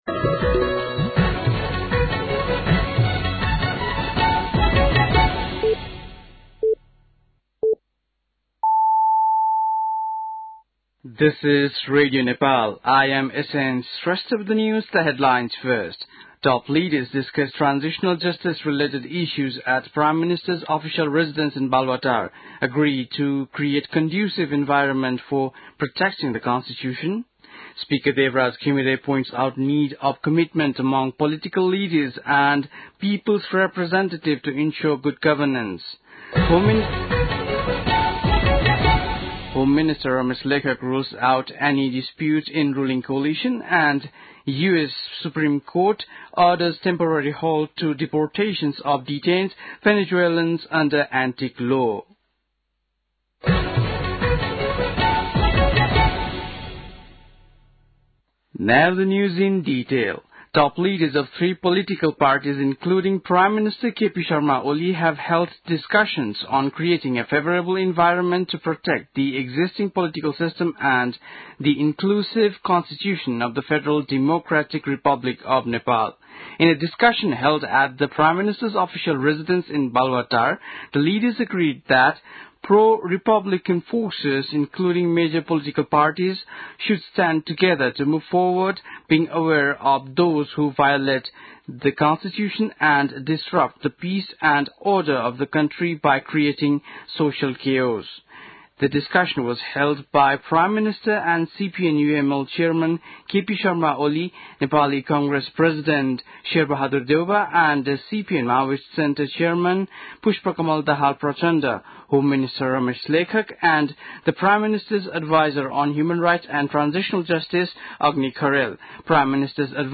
बेलुकी ८ बजेको अङ्ग्रेजी समाचार : ६ वैशाख , २०८२
8-pm-english-news-2.mp3